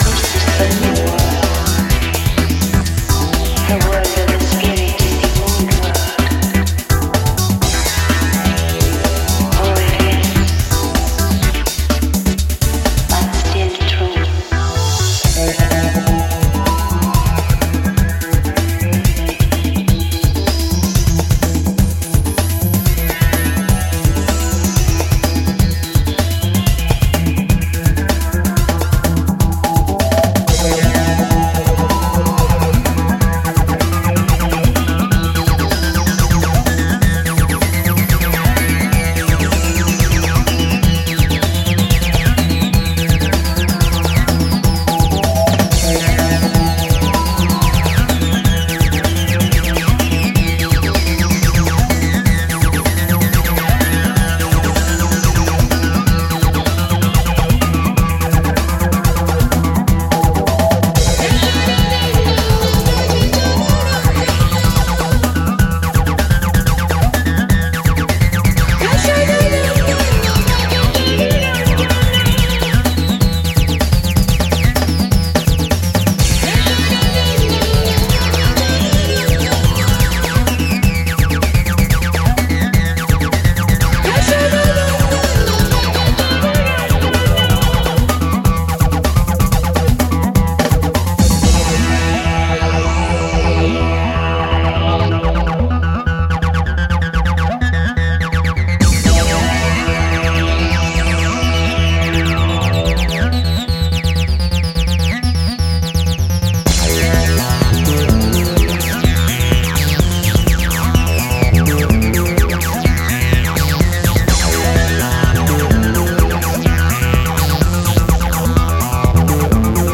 All tracks were produced in the late 90's.
retro sci-fi analogue synth vibe